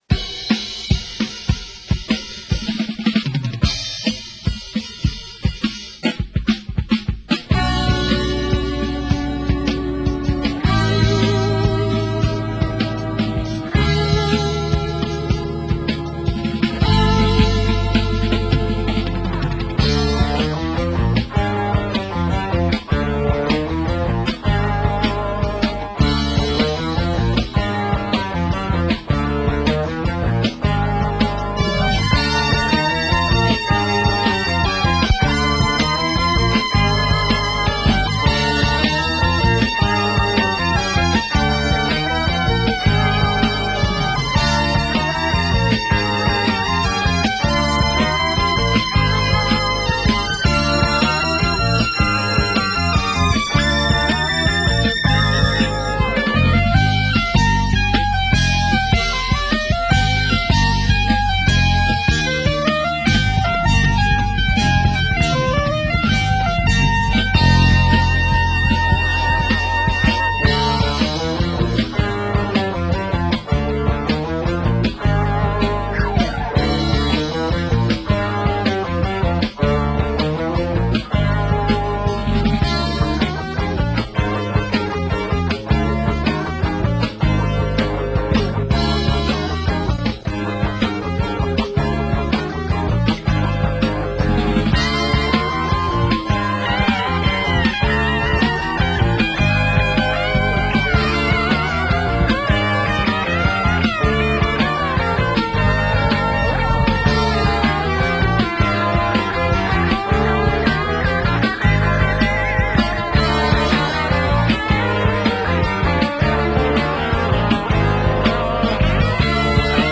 使用器材は僕の持っていたYamaha　CMX-1という4chマルチトラックカセットレコーダー。
リフ以外はその場のノリでアドリブでみんなテキトーに頑張ってました。今こうやって聞くとやっぱ80年代というより70年代の音ですね。